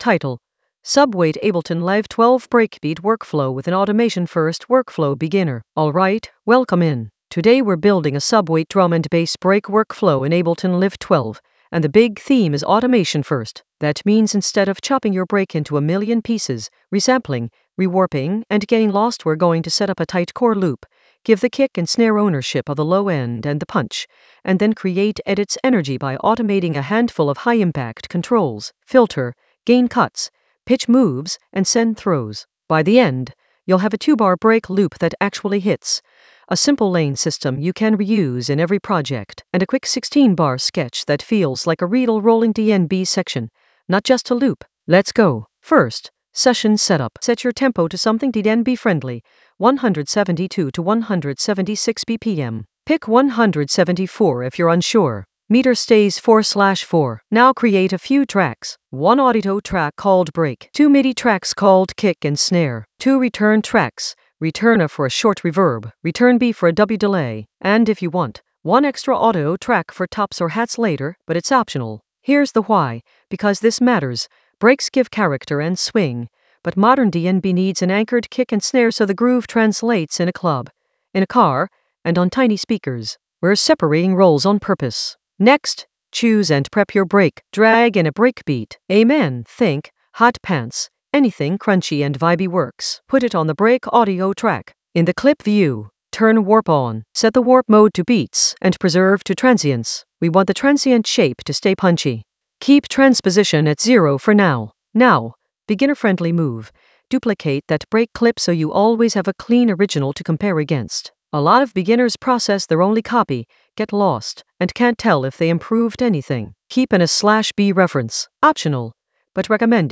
Narrated lesson audio
The voice track includes the tutorial plus extra teacher commentary.
An AI-generated beginner Ableton lesson focused on Subweight Ableton Live 12 breakbeat workflow with automation-first workflow in the Edits area of drum and bass production.